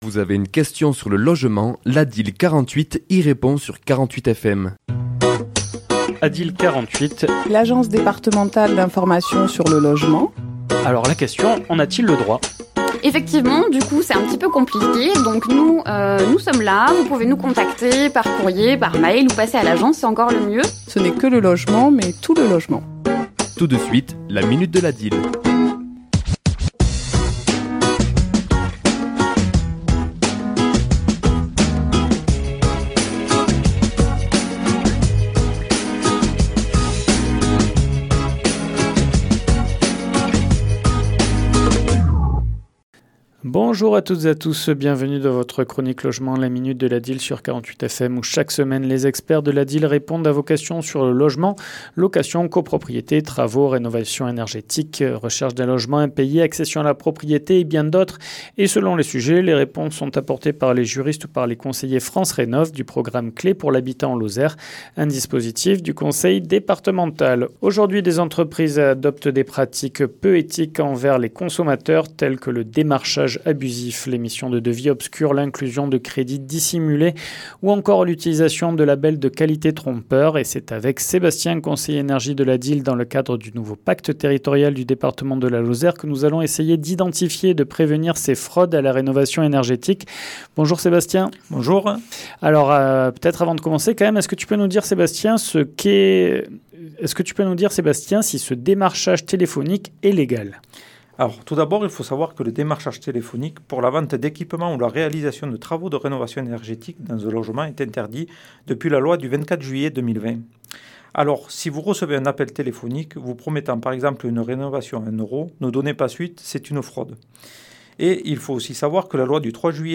ChroniquesLa minute de l'ADIL